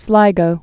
(slīgō)